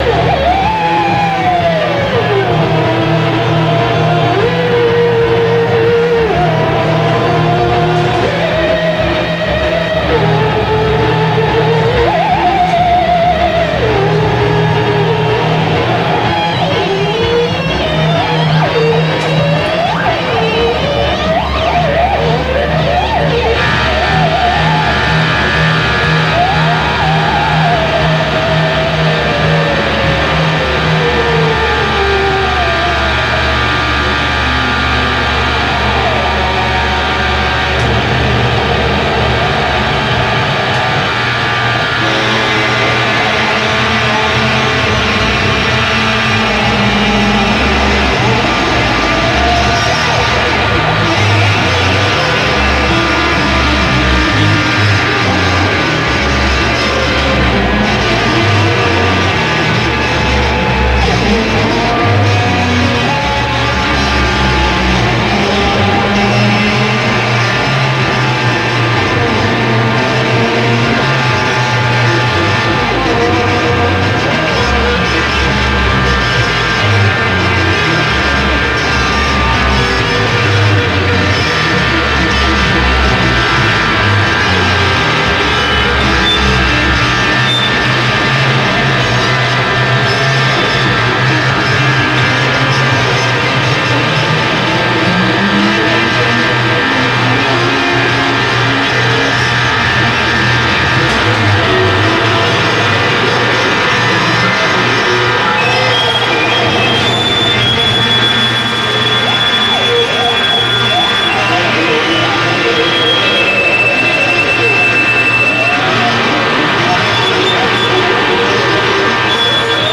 • Genre: Japanese Noise